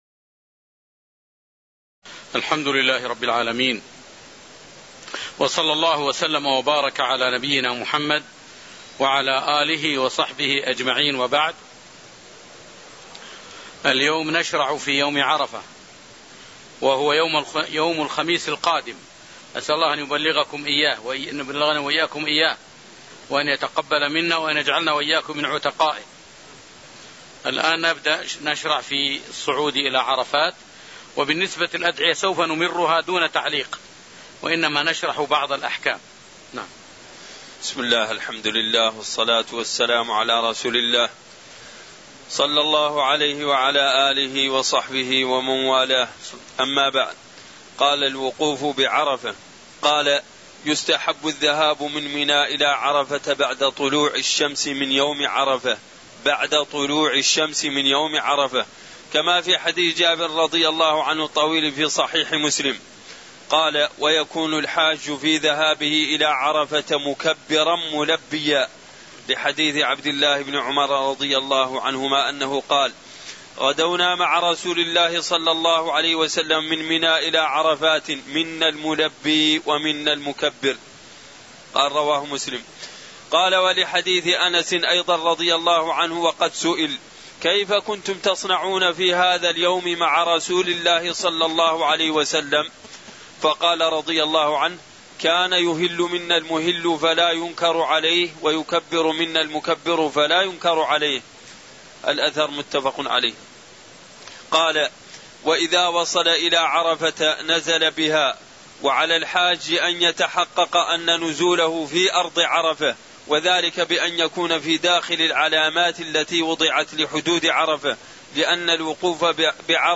تاريخ النشر ٢ ذو الحجة ١٤٣٠ هـ المكان: المسجد النبوي الشيخ